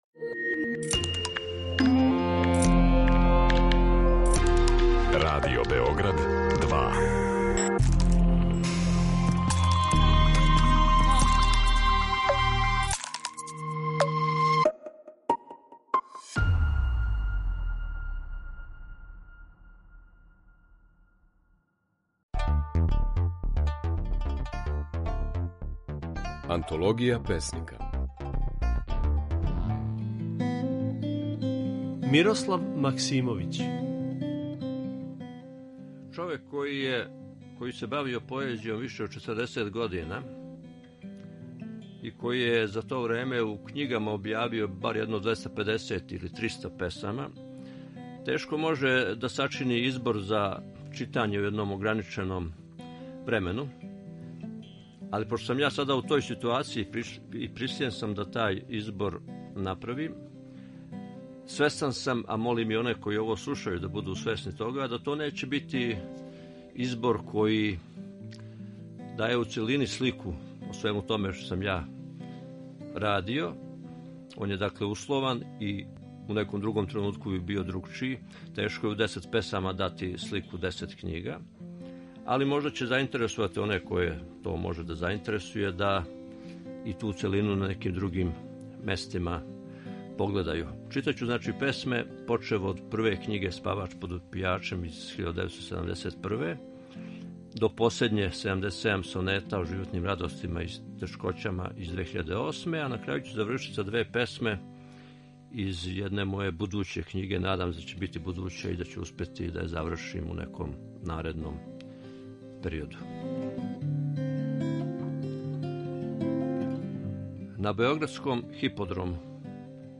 Mожете чути како своје стихове говори песник Мирослав Максимовић.
Емитујемо снимке на којима своје стихове говоре наши познати песници